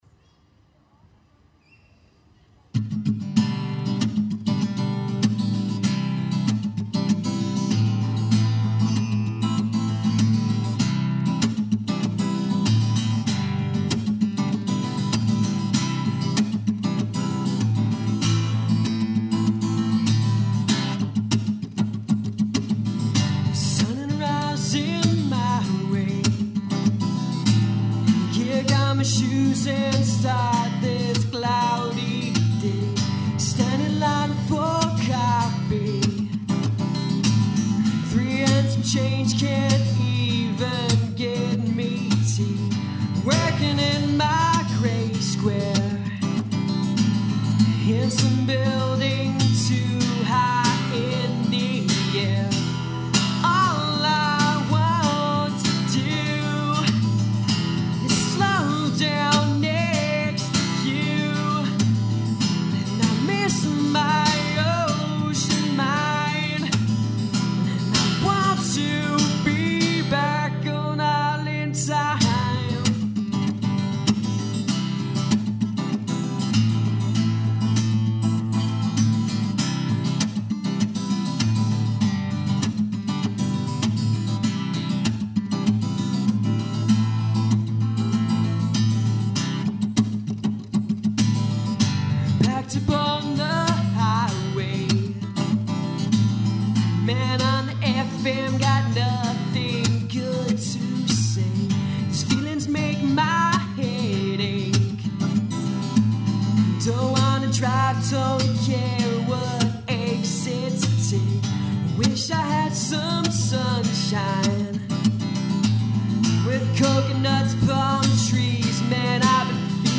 solo-acoustic original songs
A ton of thanks goes to the great people at the Music City Recording Studio for their time and effort in helping him make the demo.